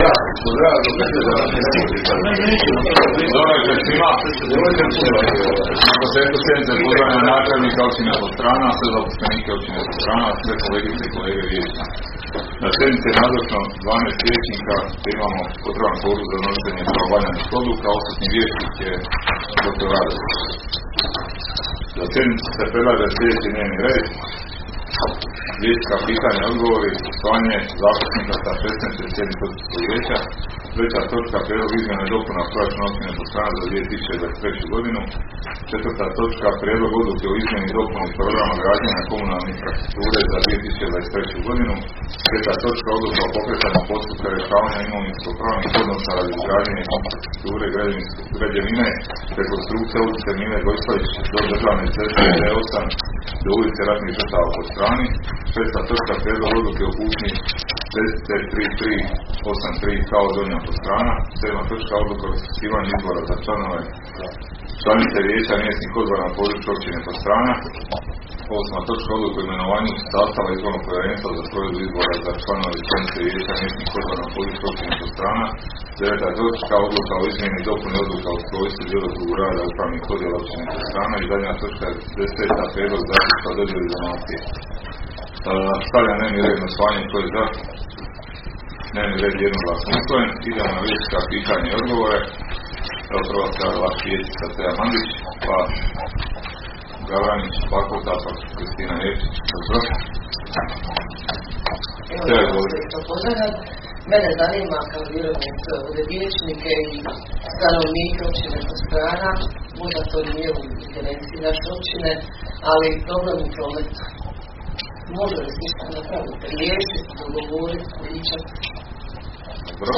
Sjednica će se održati dana 04. svibnja (četvrtak) 2023. godine u 19,00 sati u vijećnici Općine Podstrana.